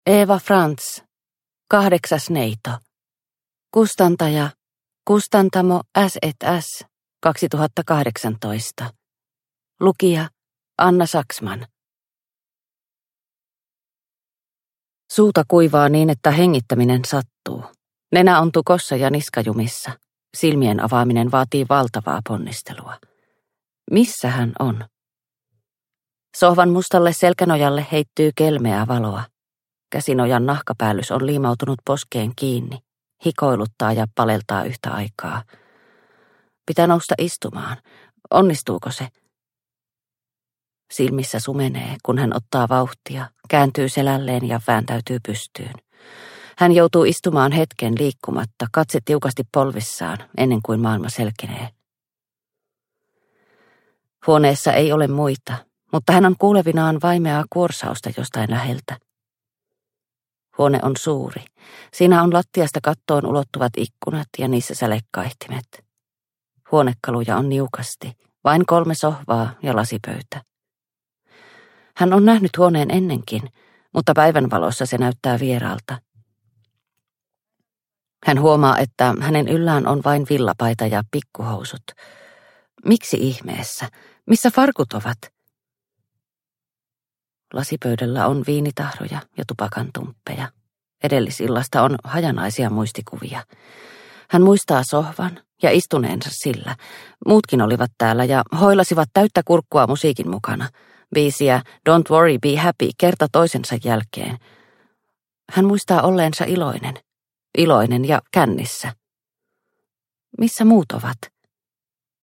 Kahdeksas neito – Ljudbok – Laddas ner